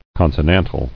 [con·so·nan·tal]